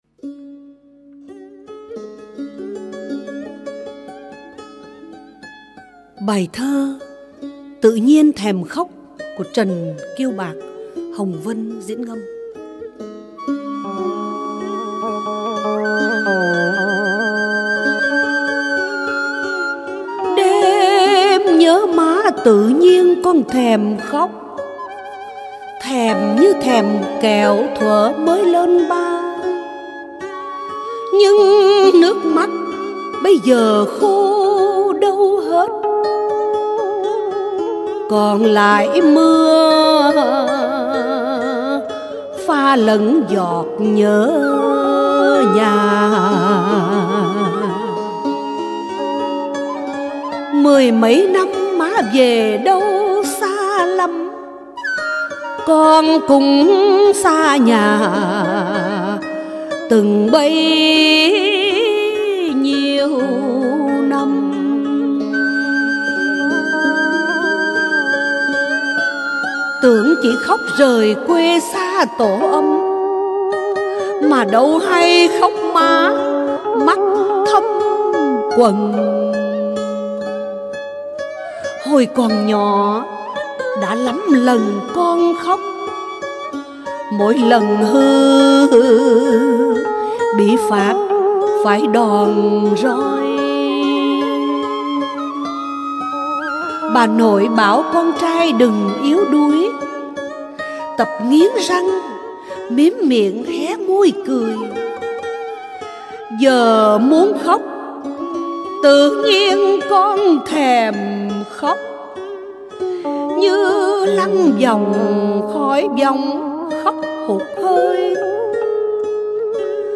Ngâm Thơ | Sáng Tạo